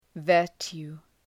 Προφορά
{‘vɜ:rtju:}